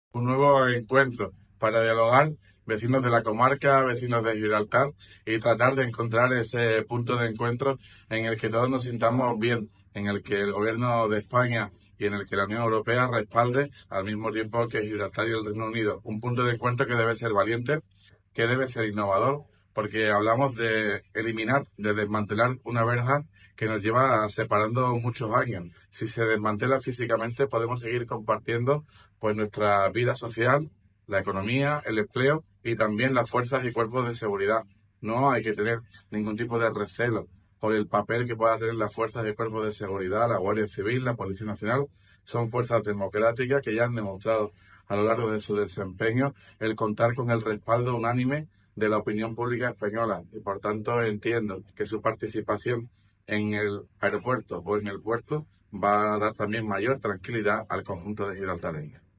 Ruiz Boix ha realizado estas declaraciones en el marco de un Coloquio sobre la situación con Gibraltar, organizado para la sección Encuentros de la publicación sanroqueña SG de Sotogrande Plus.